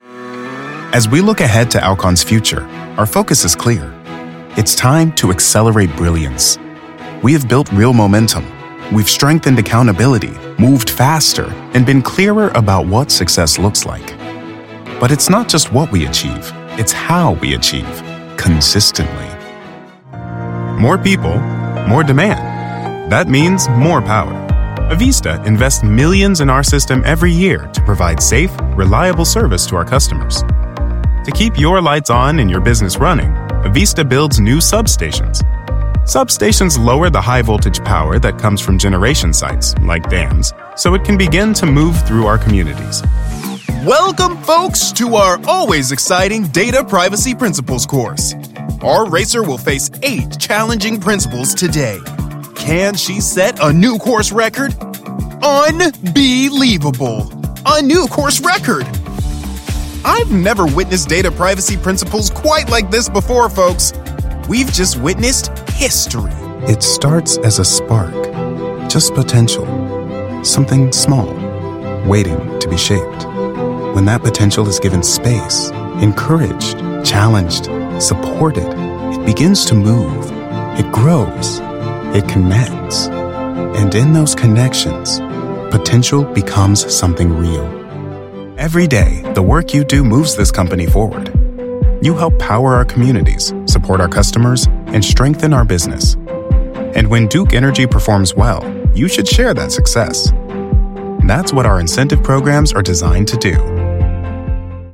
Corporate
AAVE, General English(British), US Southern, Transatlantic
New Corporate Demo 2026.mp3